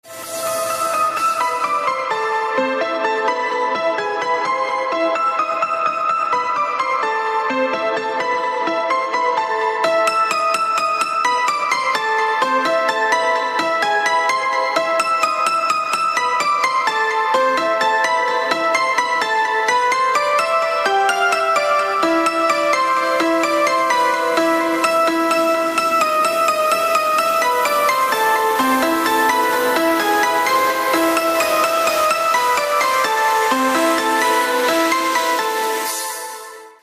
• Качество: 128, Stereo
Electronic
спокойные
без слов
клавишные
инструментальные
пианино
progressive house
классика